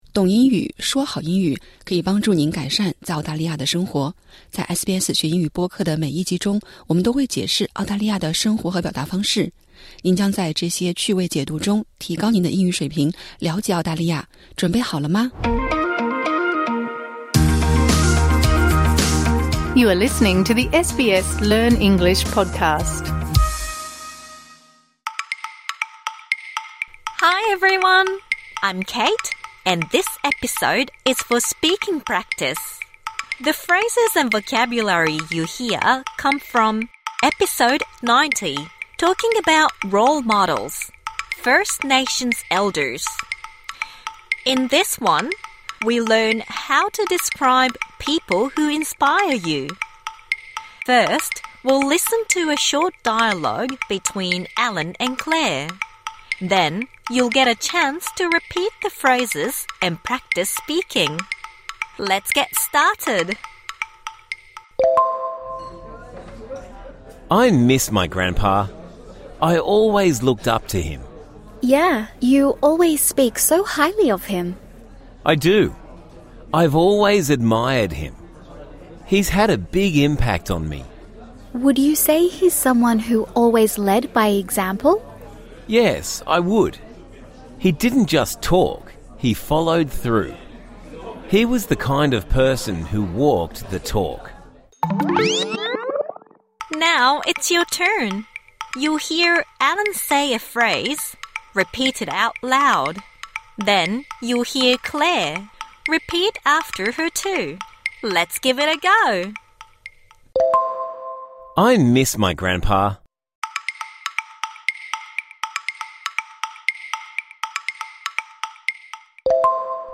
本期为《学英语》第90集的附加集，对这一集中学到的单词和短语进行互动口语练习。